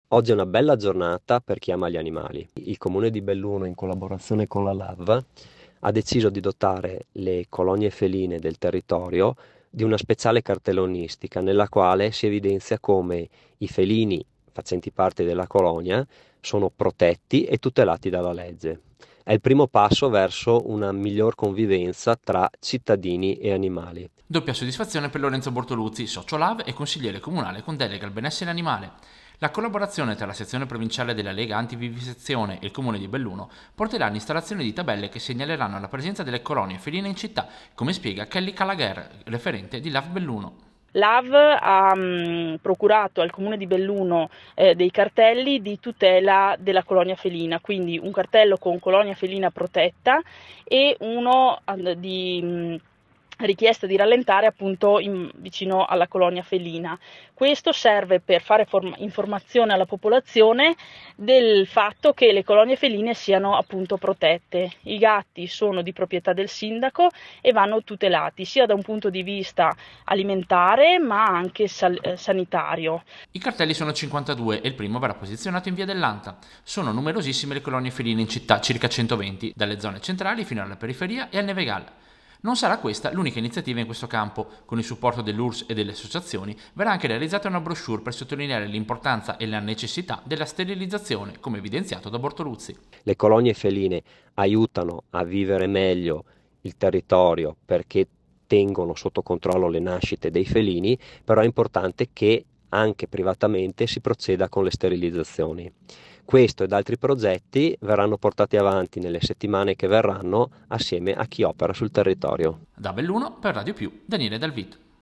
Radio-Piu-Servizio-Lav-colonie-feline-Belluno.mp3